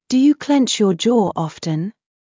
ﾄﾞｩ ﾕｰ ｸﾚﾝﾁ ﾕｱ ｼﾞｬｰ ｵｯﾌﾝ